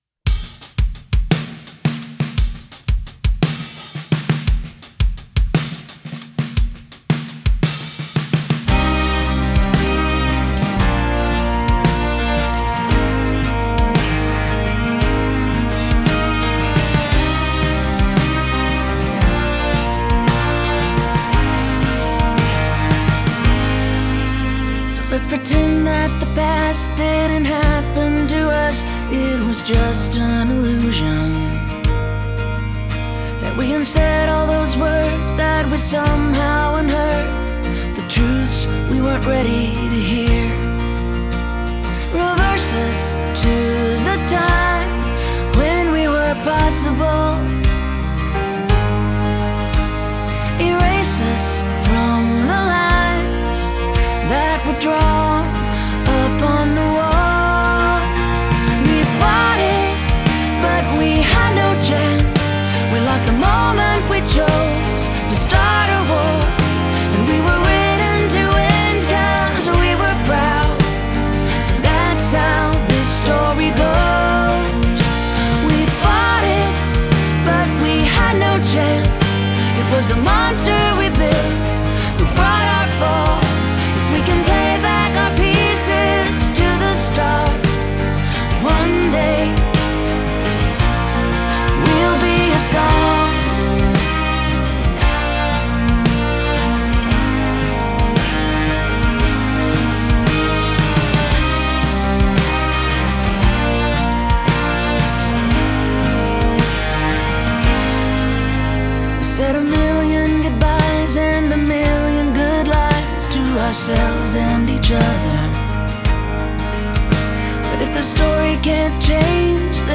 Genre: rock, pop.